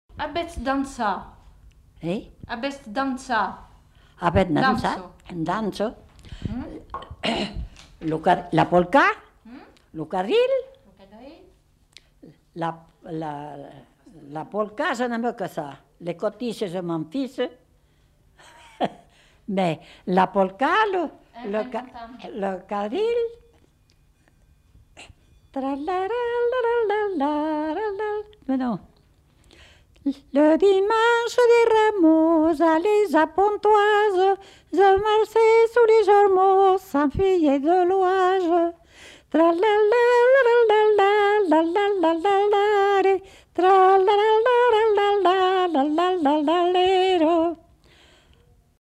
Aire culturelle : Haut-Agenais
Genre : chant
Effectif : 1
Type de voix : voix de femme
Production du son : chanté ; fredonné
Danse : quadrille